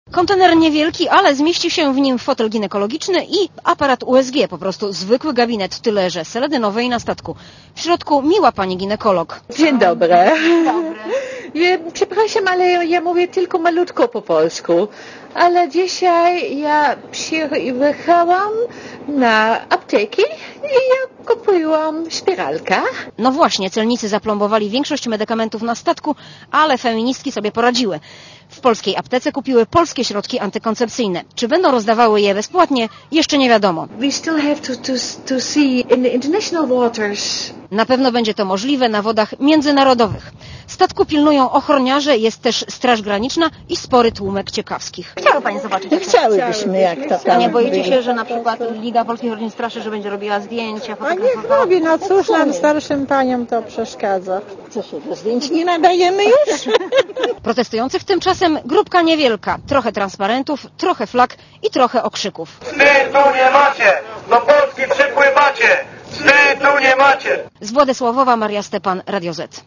Posłuchaj relacji reporterki Radia Zet (270 KB)Komentarz audio